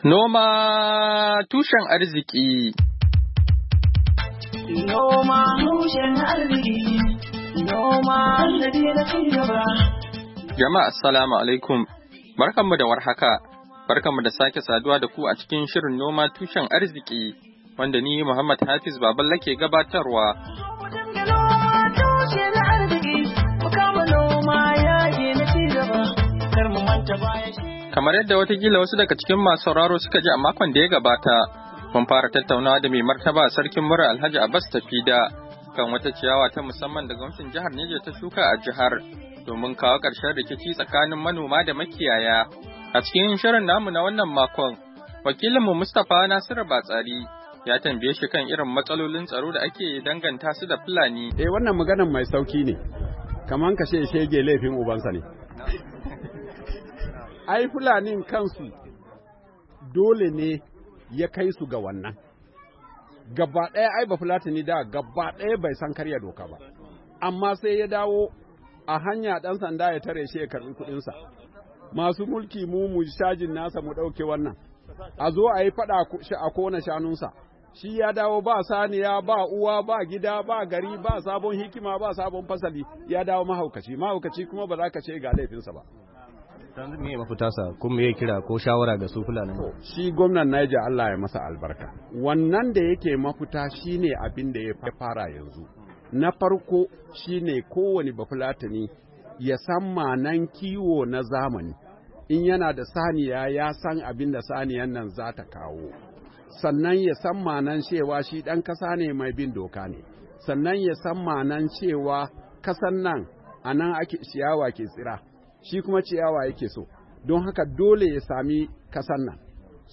NOMA TUSHEN ARZIKI: Hirar Da Mai Martaba Sarkin Muri, Alhaji Abbas Tafida, Kan Manoma Da Makiyaya, Kashi Na Biyu - 18, Agusta, 2020.